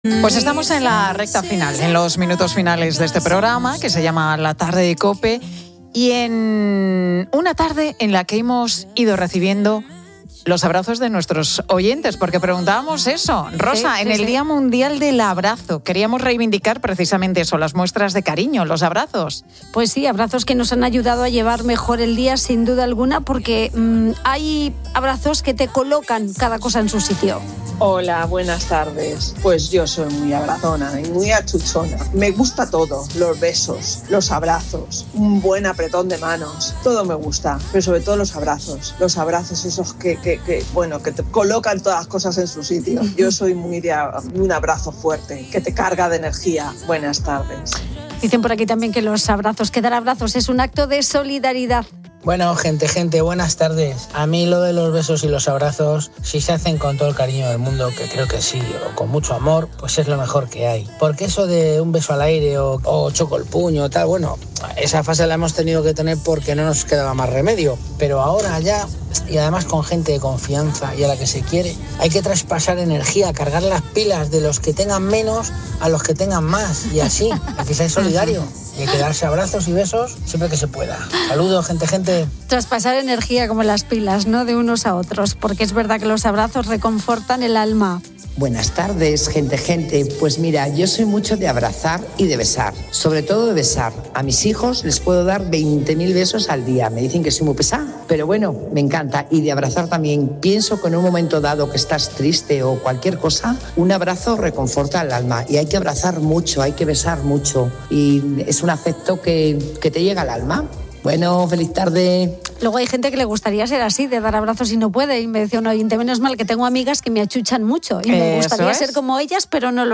Los oyentes de 'La Tarde' de COPE comparten sus historias más personales sobre el poder de un abrazo en el Día Mundial de esta muestra de afecto